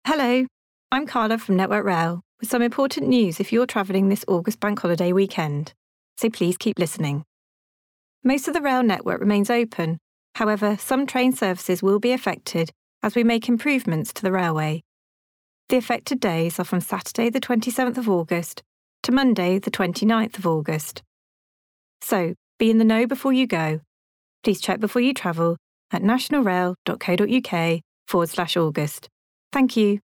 Paid Radio Ad